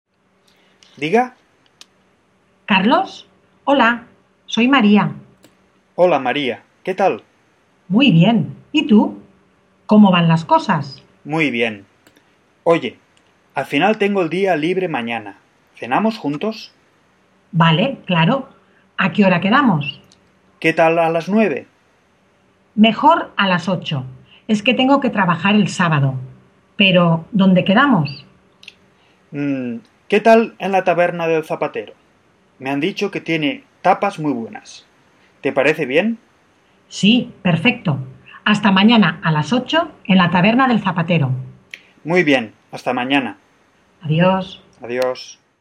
Lyssna på deras samtal utan att titta på texten. Lägg speciellt märke till uttrycken som används när man pratar i telefonen.